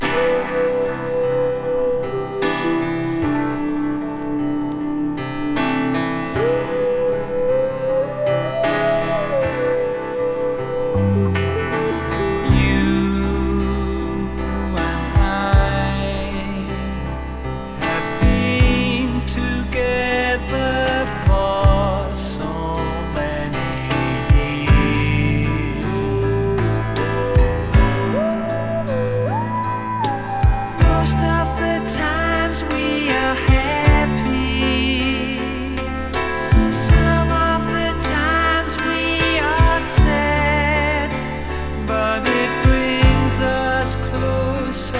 高密度なポップスを展開した宅録感満載の傑作。
Pop, Rock　Netherlands　12inchレコード　33rpm　Stereo